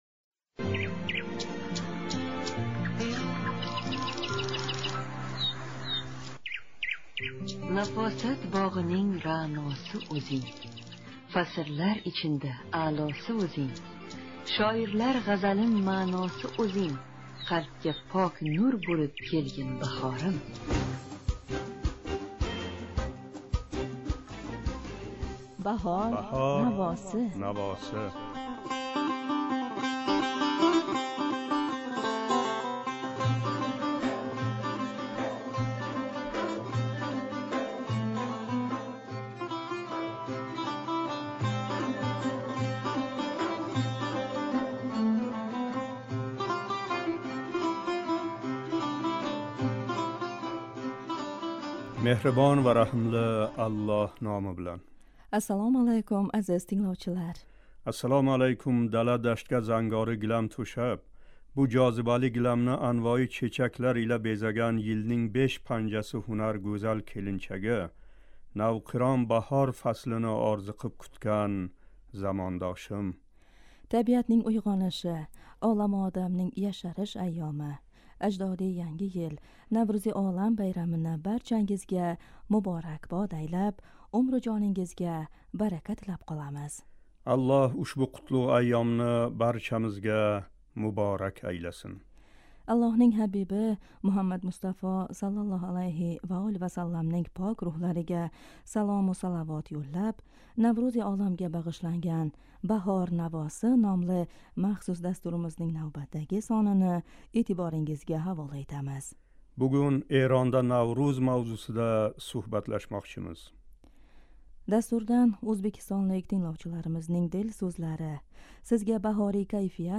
Навруз байрамига бағишланган махсус эшиттириш